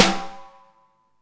808 TIN.wav